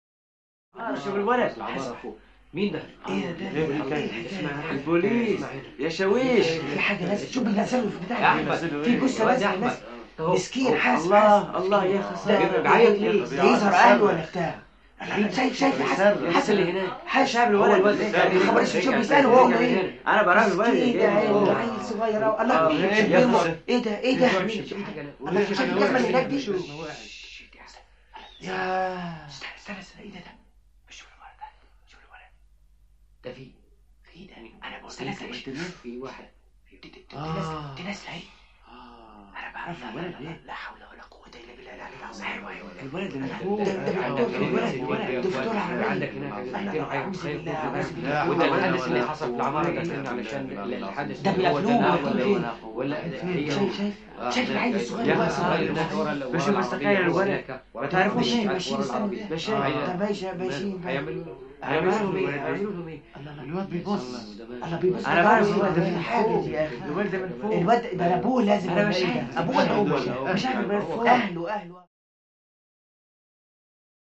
Interior Group Of Arabic Men Low Murmuring, Lapsing Into Whispers.